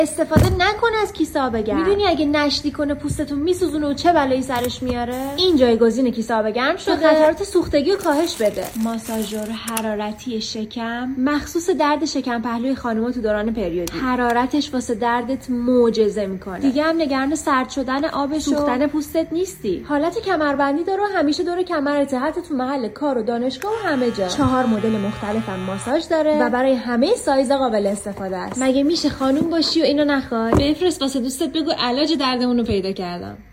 شرایط: • صدای رسا • تجربه ضبط نریشن و صداگذاری • داشتن میکروفون وظایف: • ضبط نریشن بر اساس اسکریپت • لحن غیر رسمی و غیریکنواخت در طول ریلز فایل زیر نمونه یک ریلز غیر رسمی هست بودجه ۴۰۰ هزار تومان متن ریلز بعد از پیام شما ارسال می‌گردد.